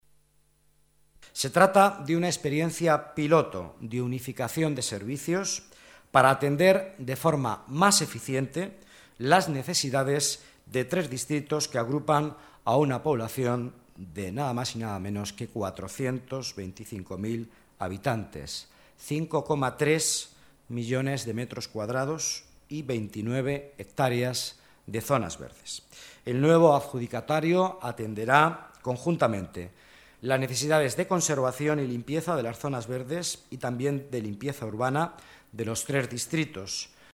Nueva ventana:Declaraciones vicealcalde, Miguel Ángel Villanueva: gestión servicios urbanos